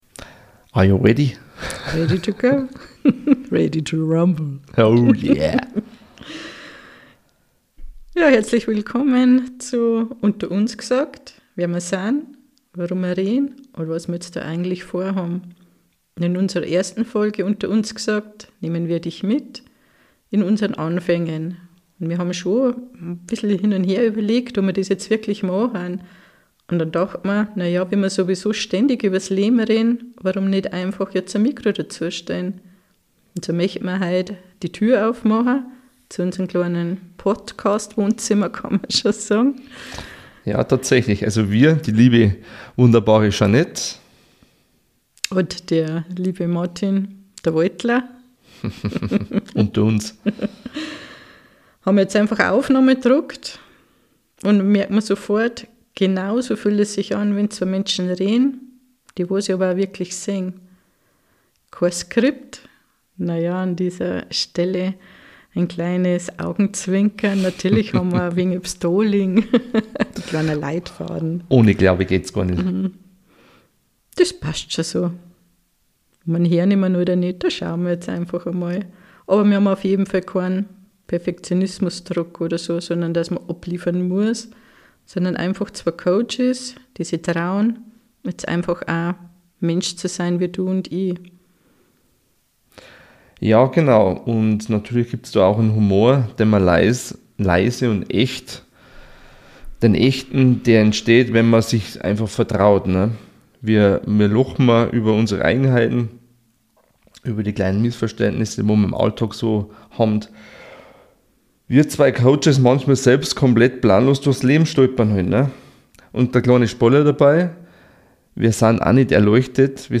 Wer wir sind und warum wir diesen ehrlichen bayrischen Realtalk starten. In dieser ersten Folge sprechen wir über Beziehungen, Selbstzweifel, Humor als Rettungsanker und darüber, wie man auch bei ernsten Themen klar und menschlich bleibt.